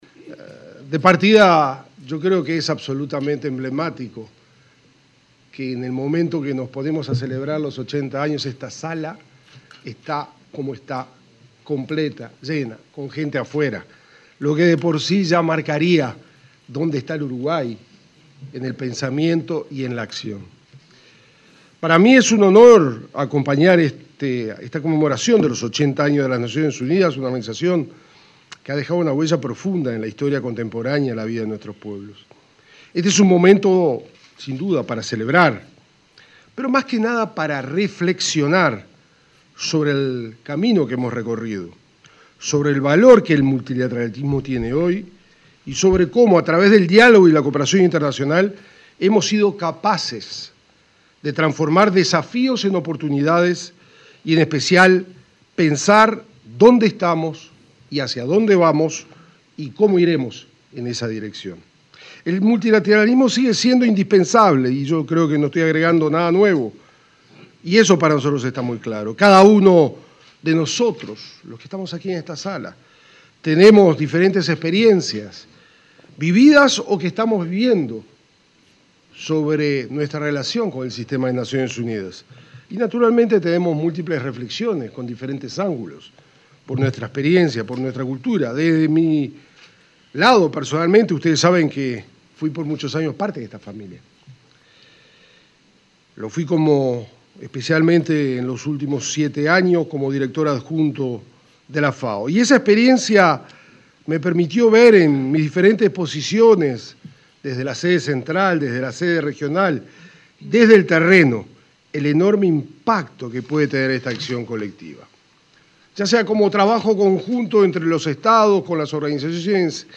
Palabras del canciller Mario Lubetkin
Palabras del canciller Mario Lubetkin 24/10/2025 Compartir Facebook X Copiar enlace WhatsApp LinkedIn Durante la conmemoración en Uruguay del 80.° aniversario de la Organización de las Naciones Unidas (ONU), se expresó el ministro de Relaciones Exteriores, Mario Lubetkin.